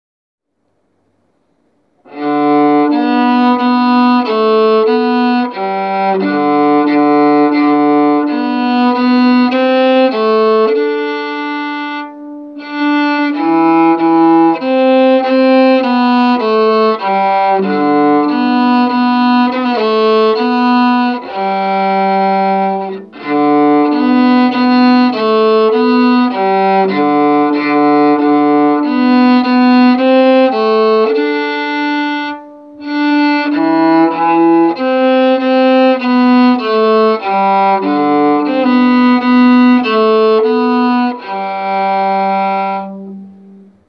Безумные безвибраторные умения (пальцы пока не окрепли для него)